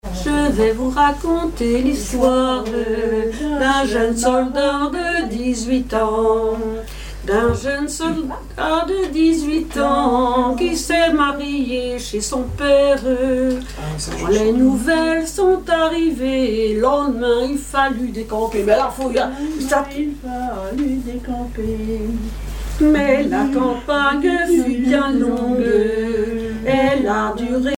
Mémoires et Patrimoines vivants - RaddO est une base de données d'archives iconographiques et sonores.
Genre strophique
Chansons et commentaires
Pièce musicale inédite